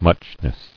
[much·ness]